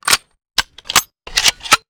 AntiTank Gun
antitank_reload_02.wav